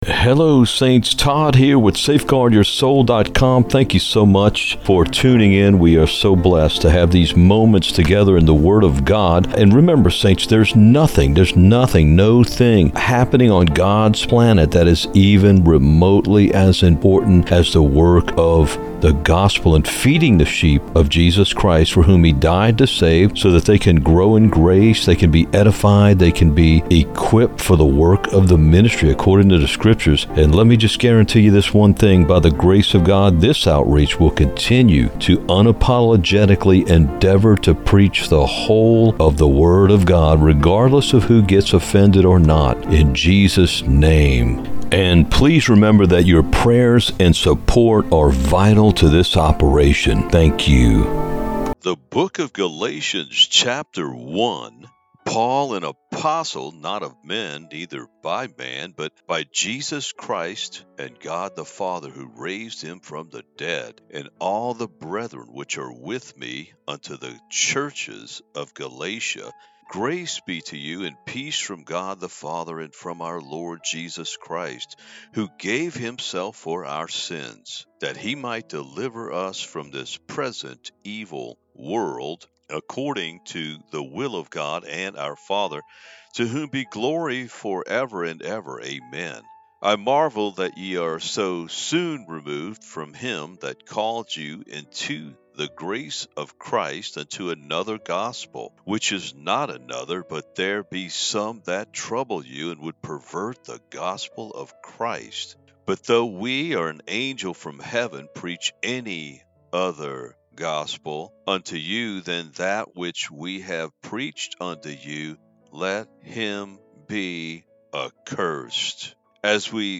The Book of Galatians Narrated
The-Book-of-Galatians-narrated-EDITED-MUSIC.mp3